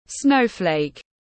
Bông tuyết tiếng anh gọi là snowflake, phiên âm tiếng anh đọc là /ˈsnəʊ.fleɪk/.